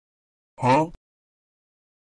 Efek suara Ceeday huh
Kategori: Suara meme
Keterangan: Efek suara Huh? Ceeday sering digunakan sebagai meme di video edit untuk menambahkan humor atau menunjukkan reaksi terkejut dan bingung saat terjadi momen lucu di dalam game.